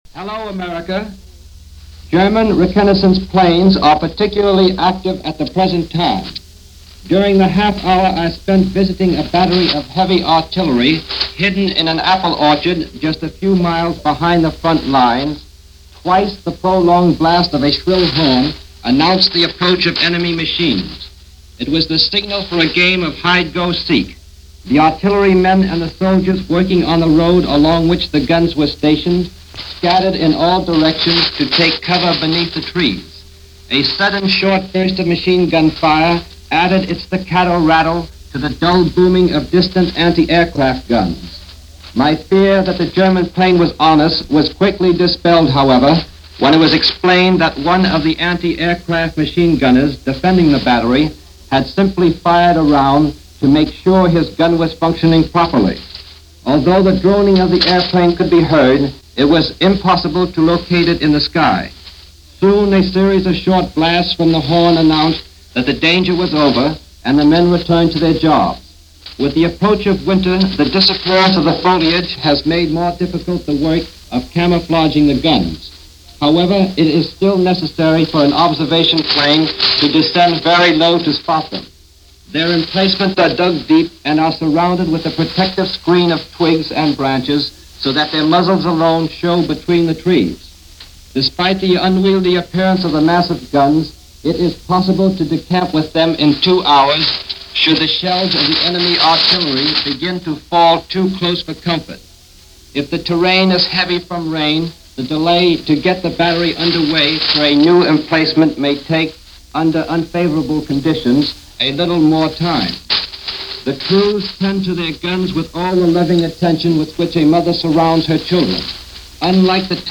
War In Slow Motion - Europe Engrossed In Conflict - November 8, 1939 - news reports on the current state of War in Europe.